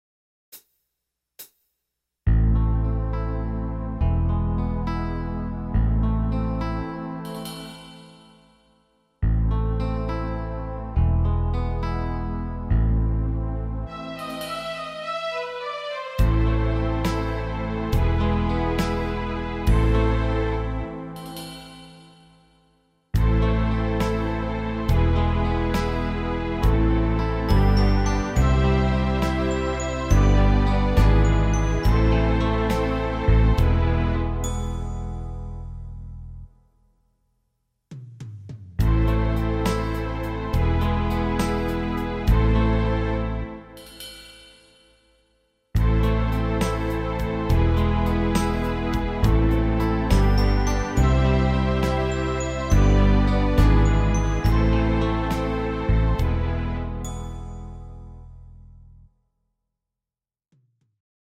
instr. Piano